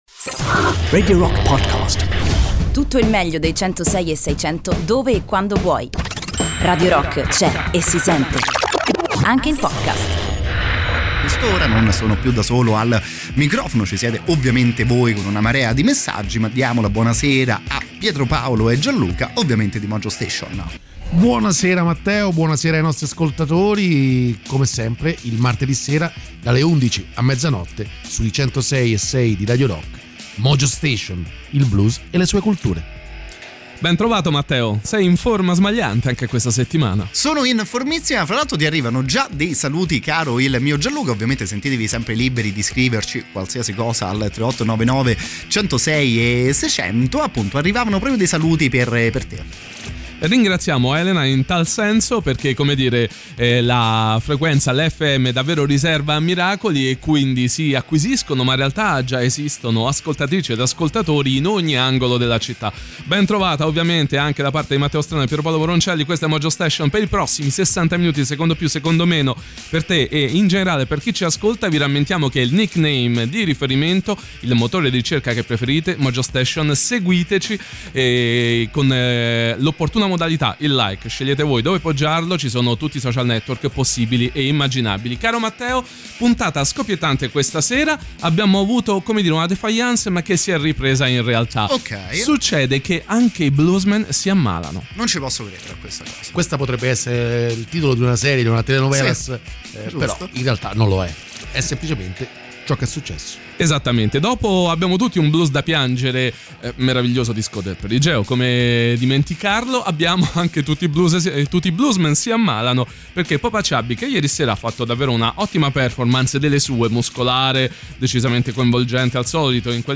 In diretta sui 106e6 di Radio Rock ogni martedì dalle 23:00